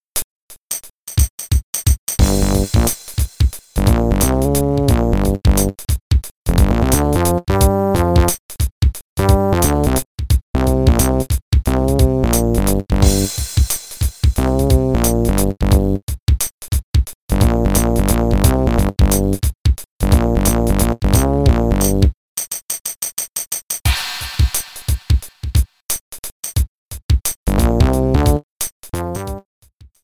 The menu theme